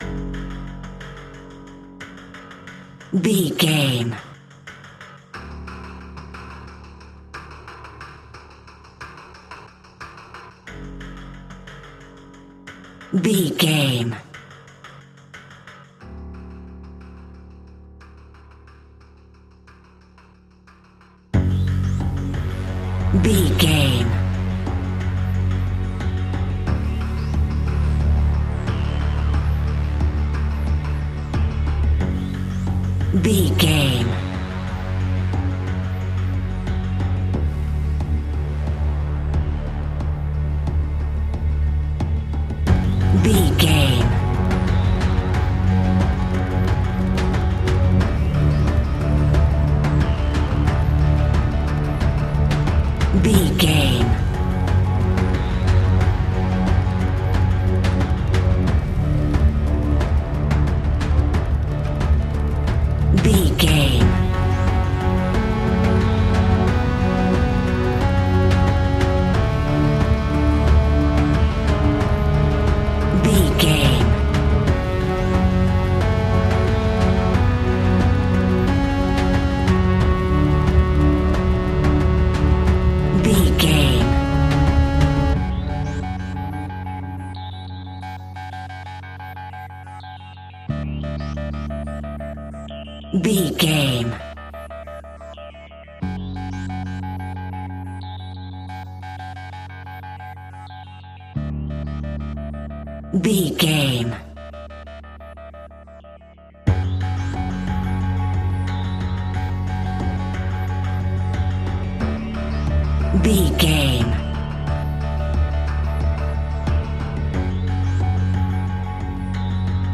In-crescendo
Aeolian/Minor
F#
ominous
dark
eerie
industrial
drums
synthesiser
percussion
strings
cello
horror music